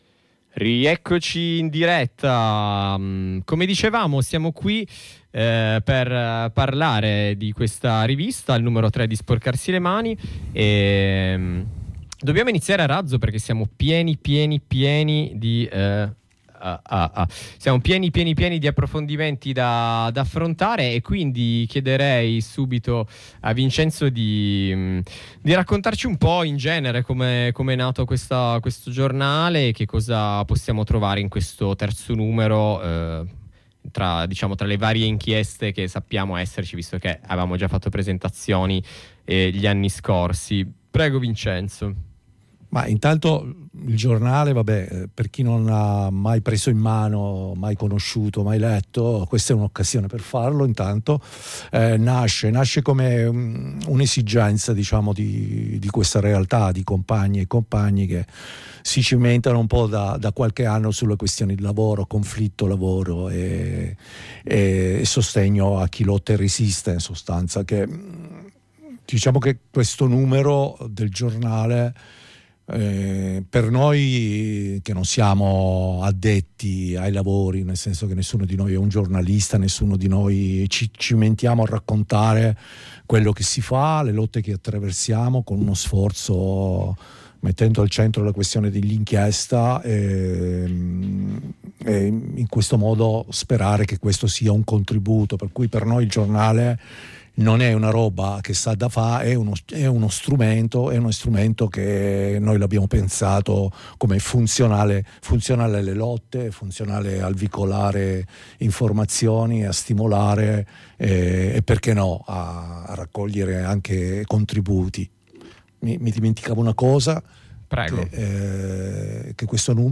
EDITORALE